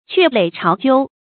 鵲壘巢鳩 注音： ㄑㄩㄝˋ ㄌㄟˇ ㄔㄠˊ ㄐㄧㄨ 讀音讀法： 意思解釋： 同「鵲巢鳩居」。